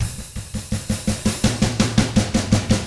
164ROCK F2-R.wav